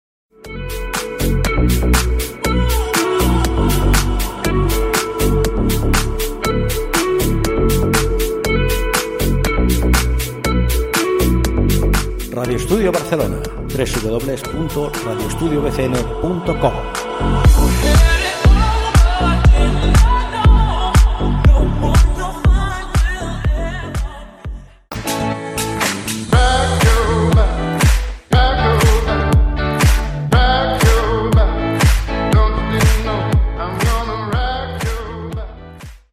Indicatiu de la ràdio i tema musical